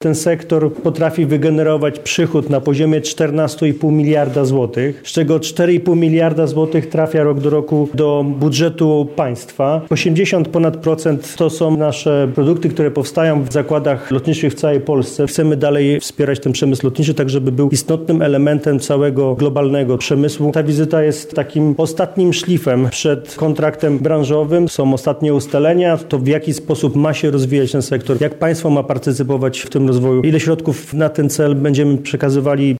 Celem wizyty było zapowiedzenie kontraktu branżowego dla sektora lotniczego. Jak podkreślił w rozmowie z dziennikarzami Michał Jaros, przemysł lotniczy odgrywa istotną rolę w polskiej gospodarce.